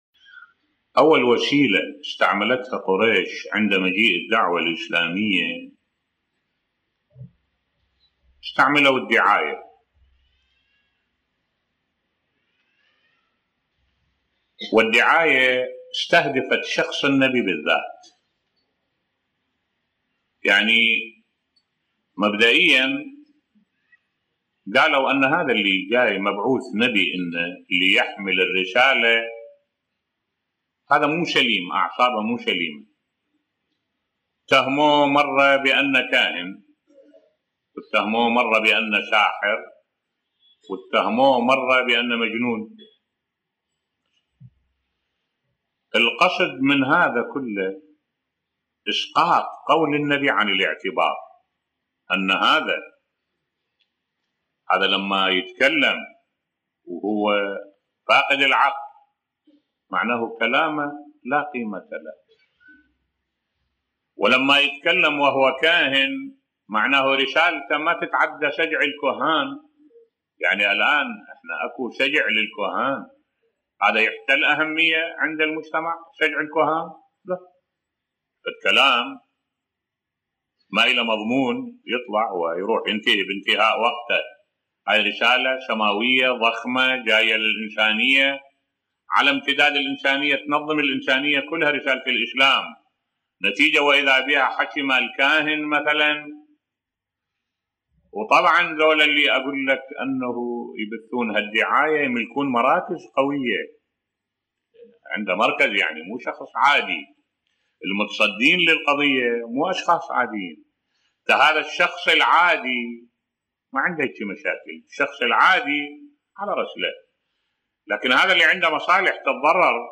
ملف صوتی العقد الشرعي يضمن حقوق أفراد الاسرة بصوت الشيخ الدكتور أحمد الوائلي